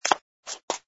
sfx_fturn_female01.wav